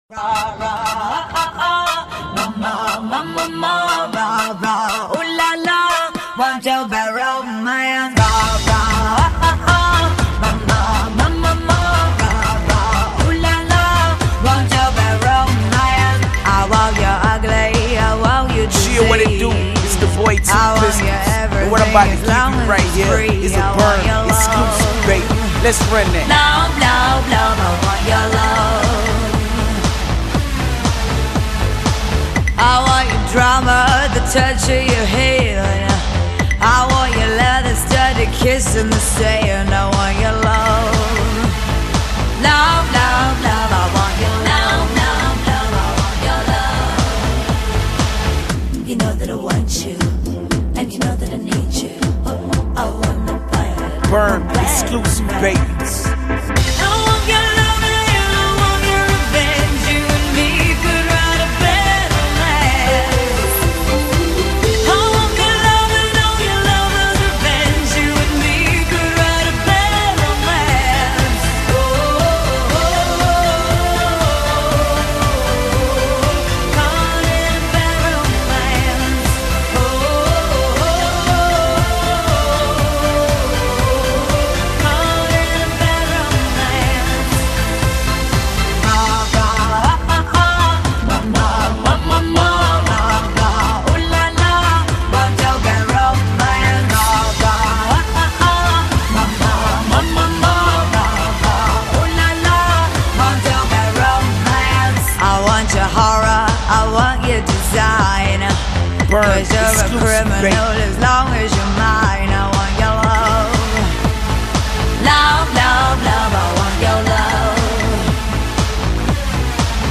зарубежная эстрада